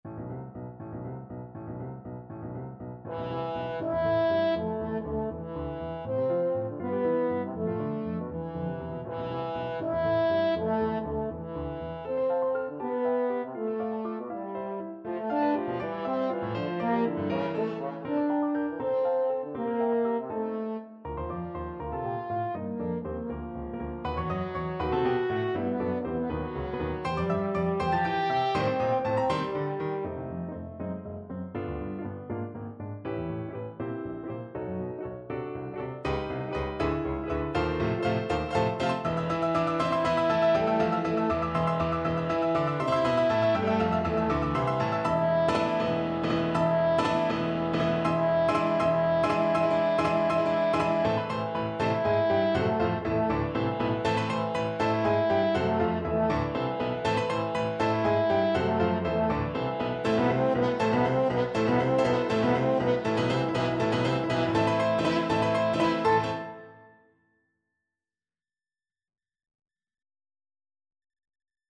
French Horn
A minor (Sounding Pitch) E minor (French Horn in F) (View more A minor Music for French Horn )
3/4 (View more 3/4 Music)
~ = 100 Sehr schnell (.=80)
Classical (View more Classical French Horn Music)
bruckner_sym7_scherzo_HN.mp3